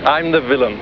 Darwin Mayflower is played by Richard E Grant.